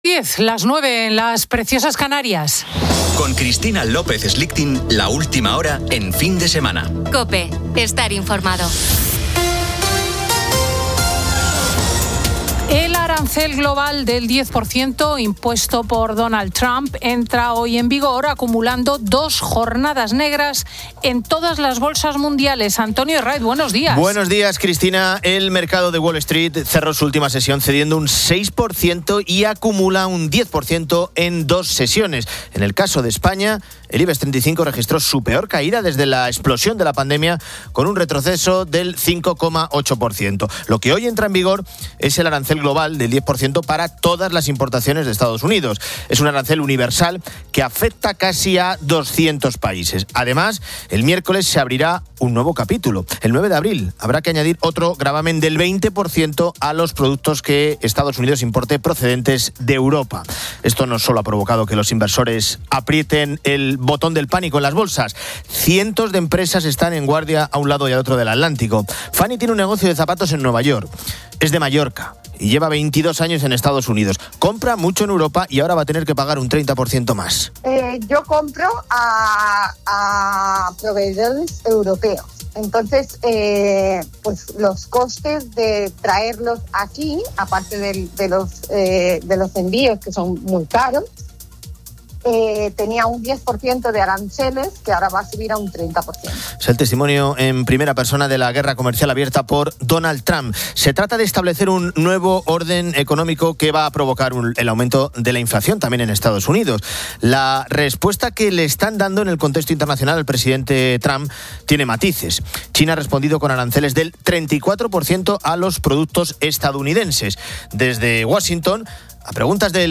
Fin de Semana 10:00H | 05 ABR 2025 | Fin de Semana Editorial de Cristina López Schlichting.